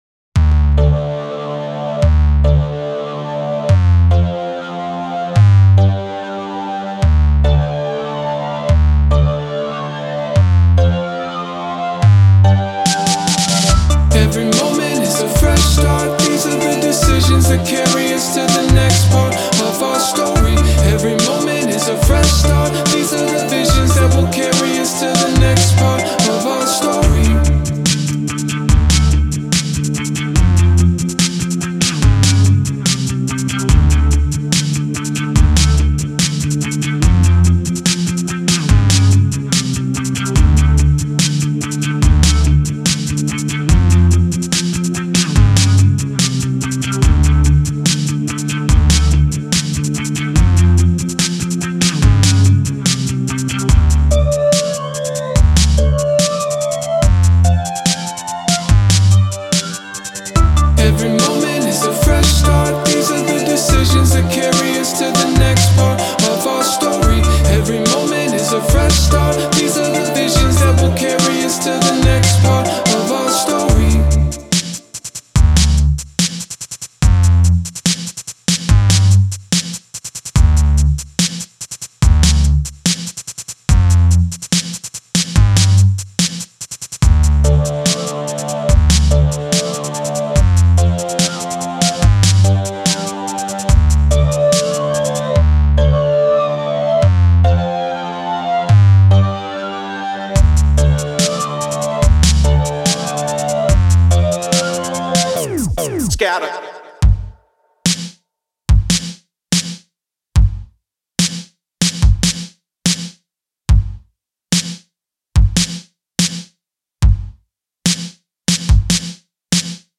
fun, high energy hyper pop song